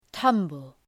Προφορά
{‘tʌmbəl}